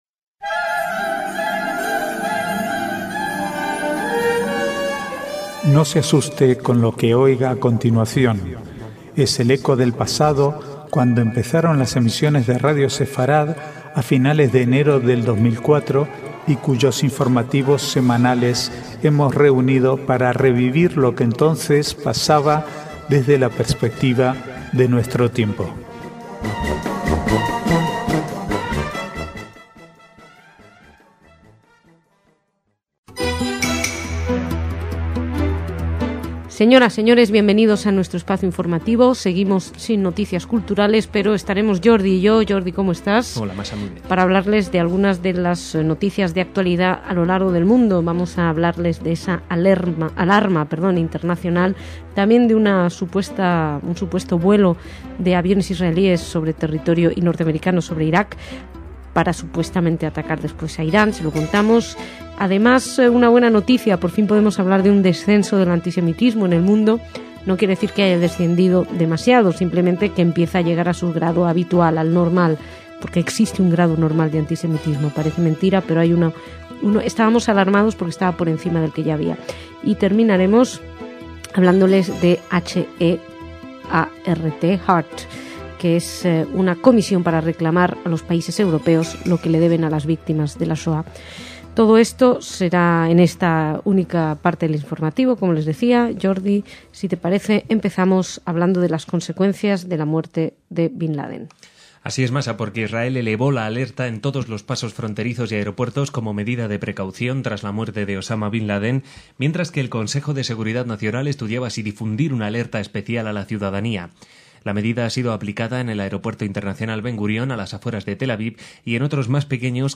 Archivo de noticias del 4 al 10/5/2011